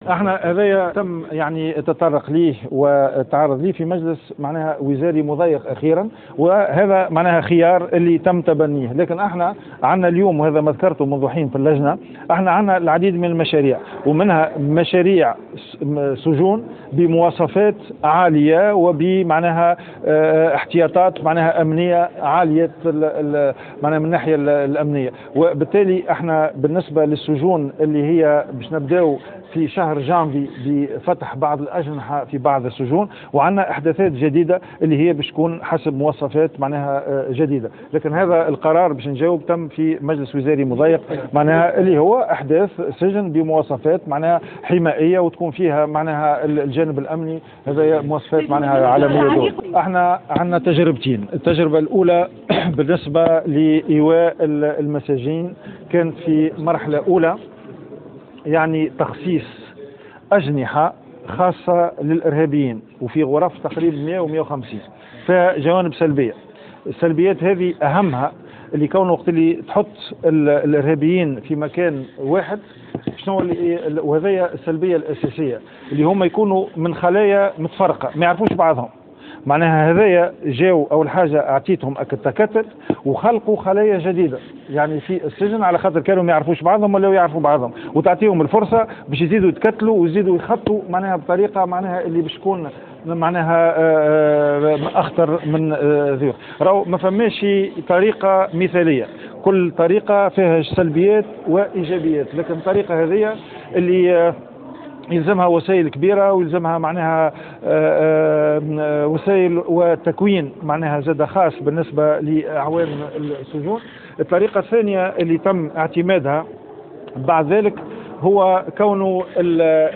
أكد وزير العدل غازي الجريبي، اليوم خلال جلسة استماع صلب لجنة الأمن والدفاع بمجلس نواب الشعب أن مجلسا وزاريا مضيقا قرر بناء سجن خاص بالإرهابيين حسب مواصفات أمنية خاصة.